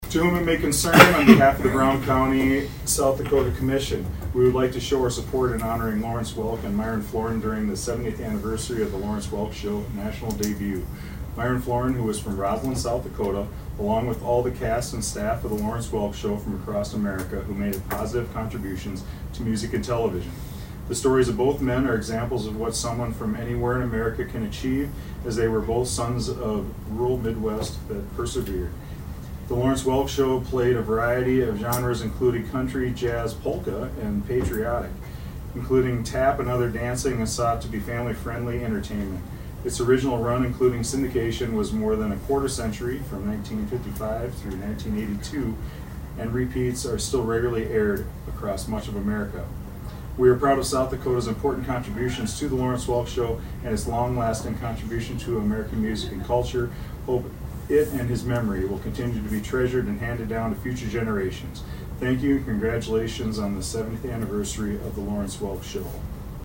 ABERDEEN, S.D.(HubCityRadio)- At Tuesday’s Brown County Commission meeting, they addressed a proclamation recognizing the Lawrence Welk Show which debuted back in 1955.
Brown County Commissioner Mike Gage read the proclamation.